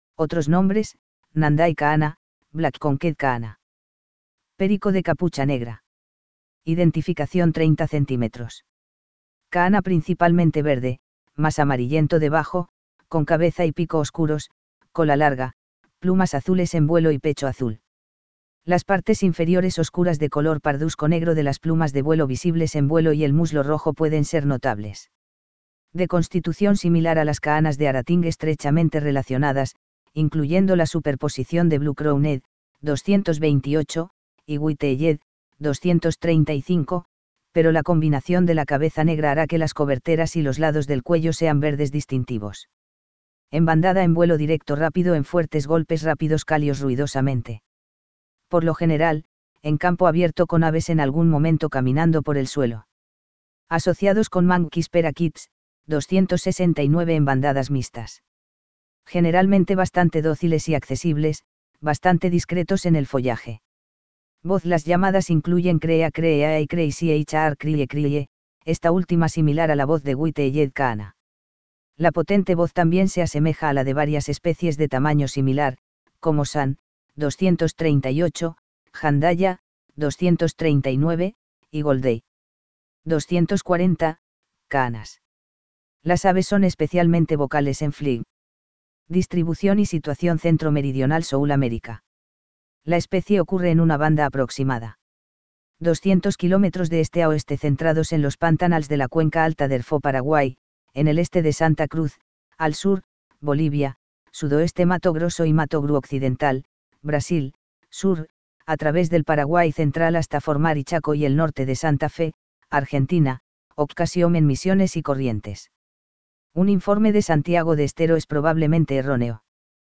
Nandayus nenday - Loro cabeza negra
VOZ Las llamadas incluyen kree-ah kree-ah y krehh y chr ■ chriie chriie, esta última similar a la voz de White-eyed Conure.
lorocabezanegra.mp3